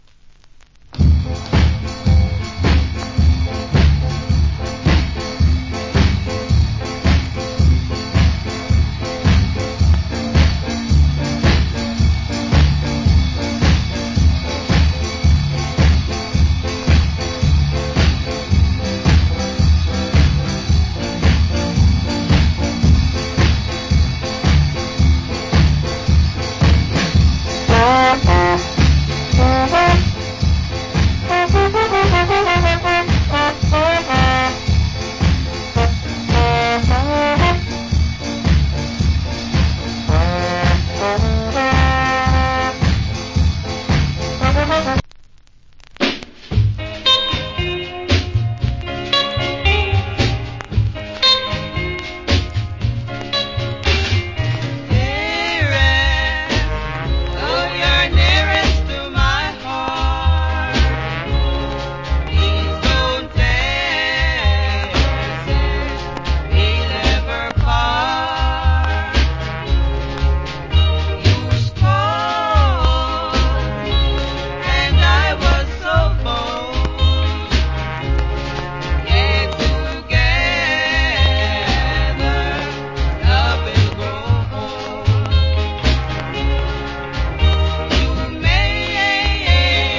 Early 60's Jamaican Shuffle Inst.